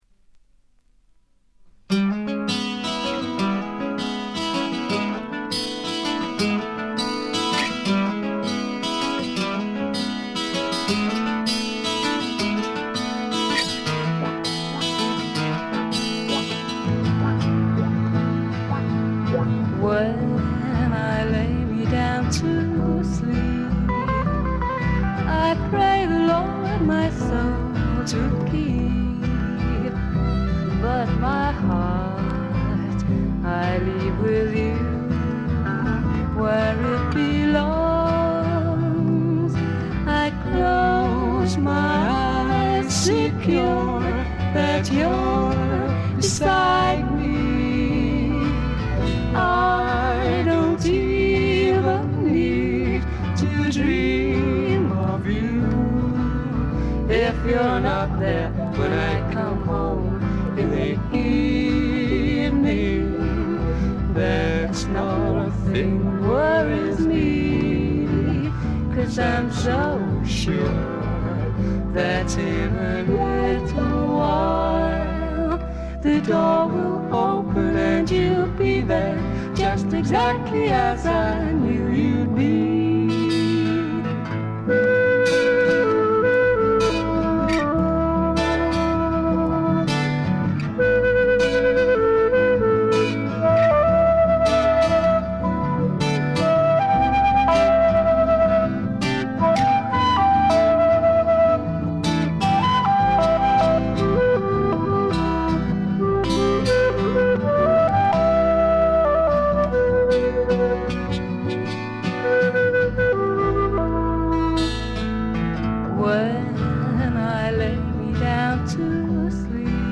ところどころでチリプチやバックグラウンドノイズ。
試聴曲は現品からの取り込み音源です。